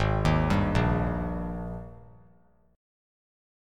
AbM7sus2 Chord
Listen to AbM7sus2 strummed